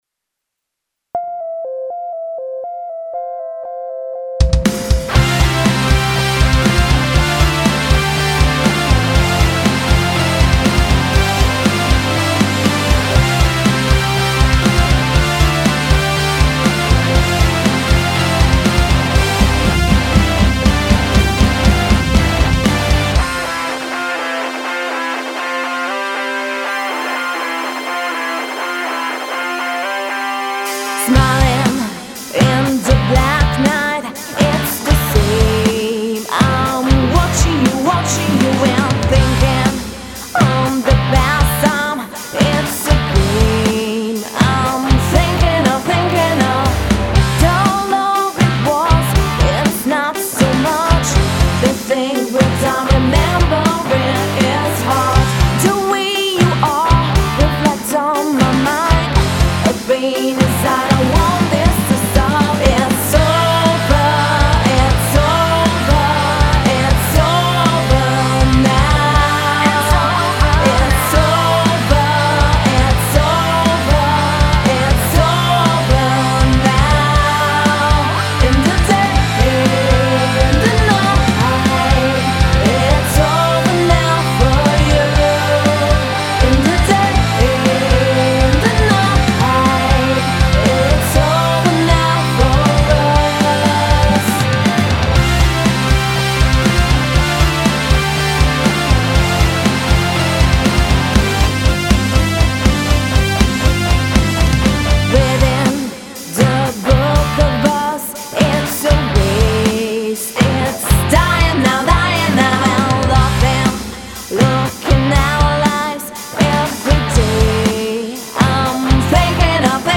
Genere: Pop/Rock